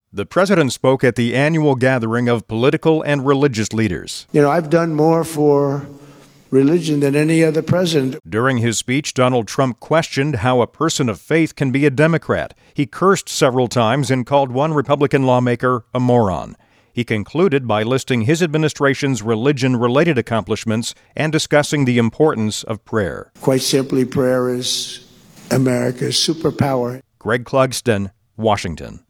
President Trump delivered an unusual speech at the 74th annual National Prayer Breakfast.